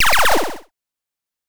8bit_FX_Shot_02_01.wav